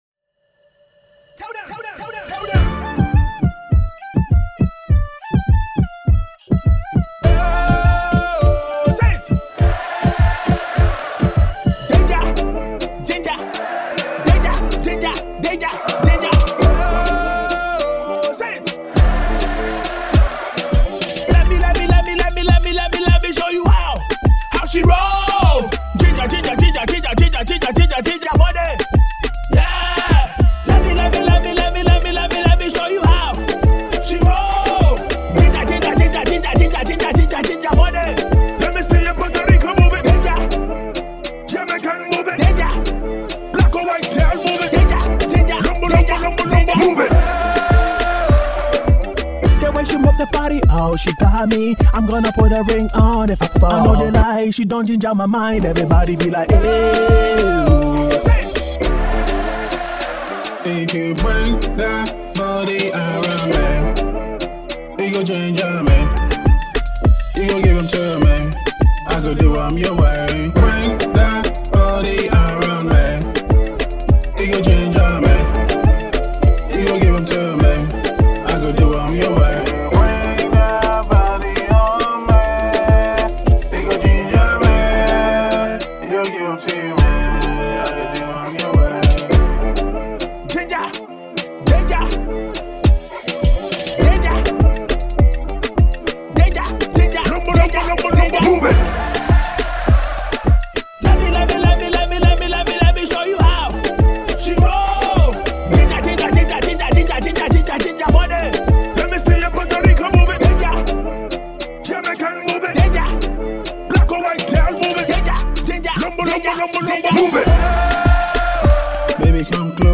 Afro-Swing and Afrobeats
Faster Version 2